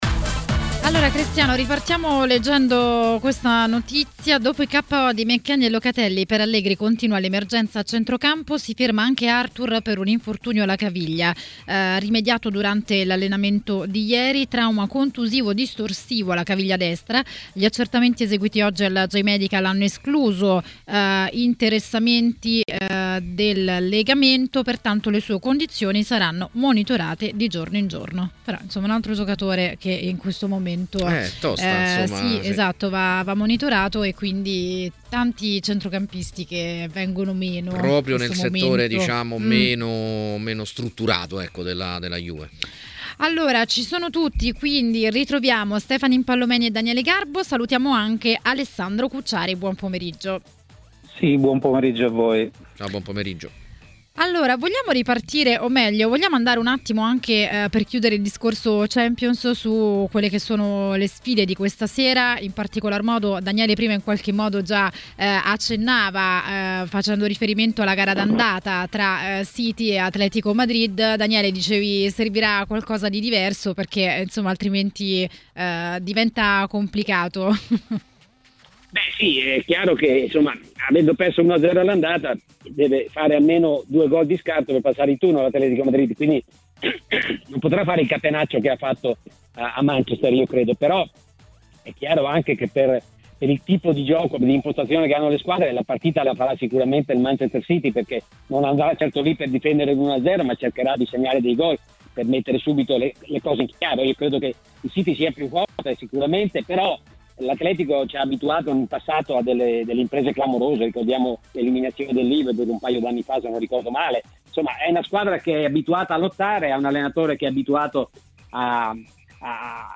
a TMW Radio, durante Maracanà, ha parlato dei temi del giorno.